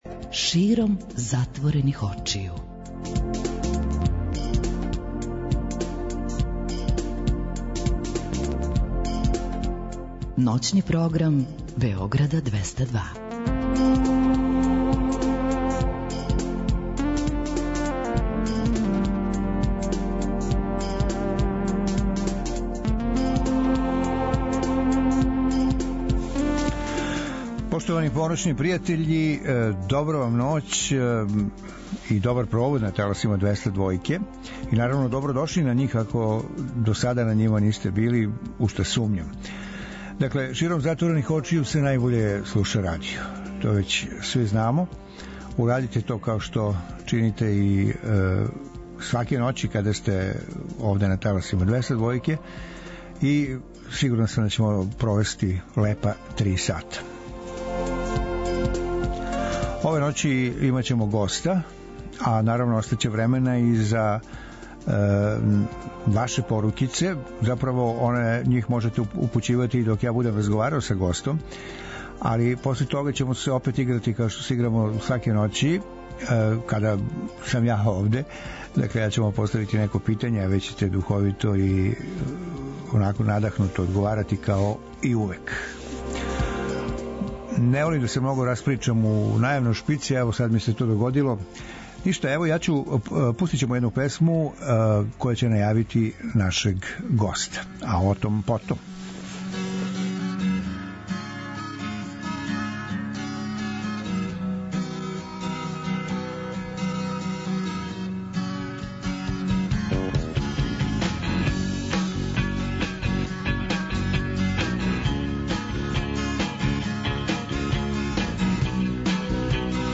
Остатак емисије, биће посвећен контакту са слушаоцима и као и обично, њиховим одговорима на питања која ће им бити постављена, а све то уз обиље квалитетне музике.